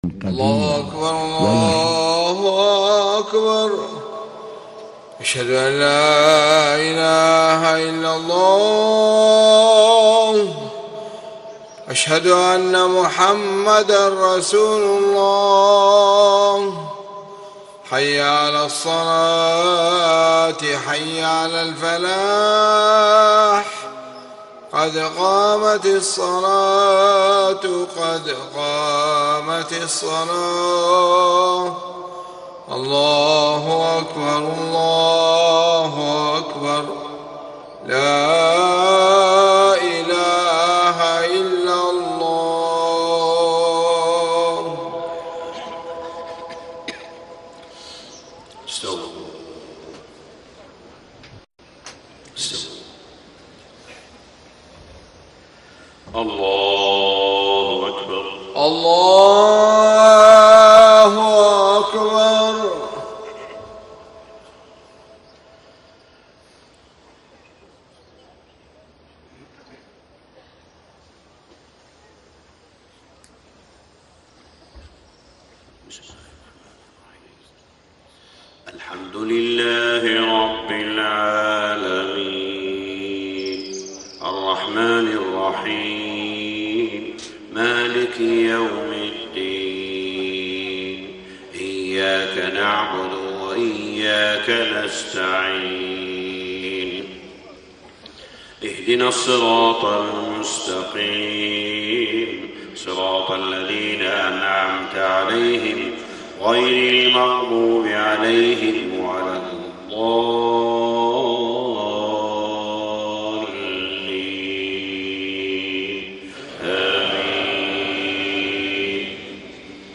صلاة الفجر 4-5-1434 من سورة البقرة 278-286 > 1434 🕋 > الفروض - تلاوات الحرمين